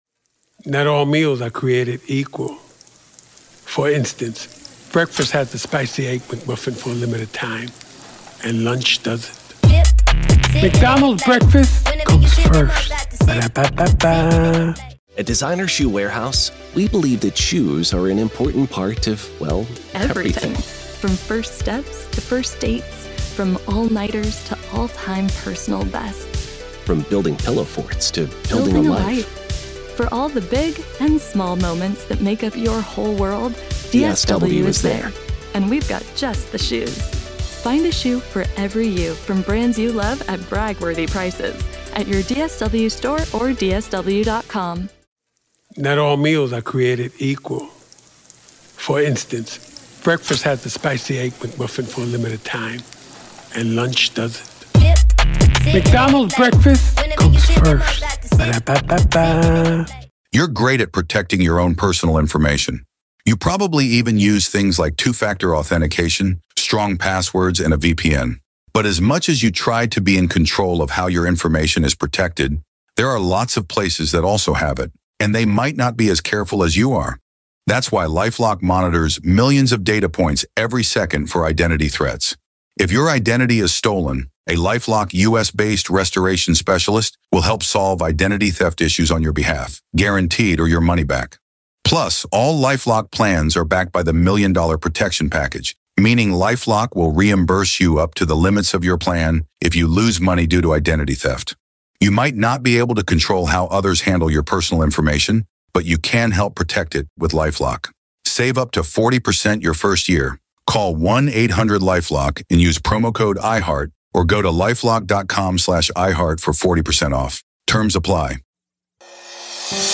In a compelling discussion